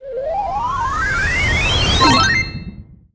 enemy_char_respawn_01.wav